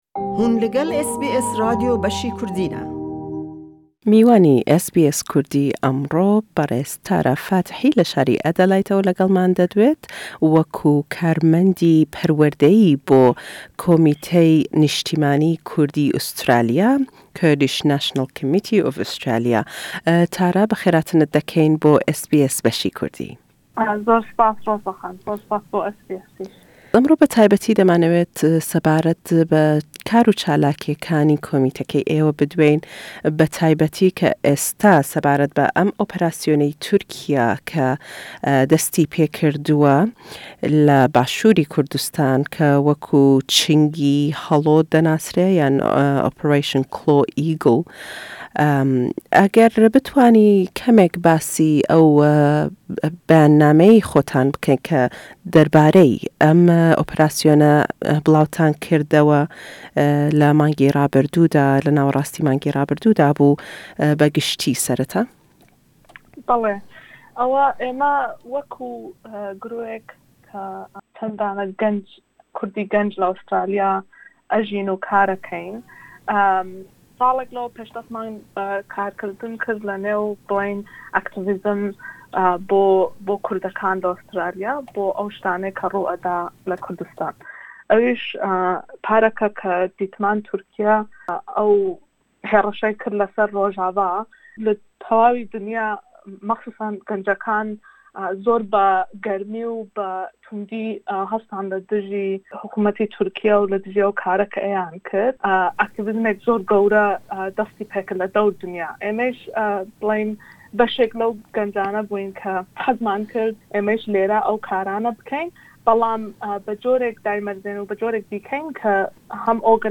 lêdwane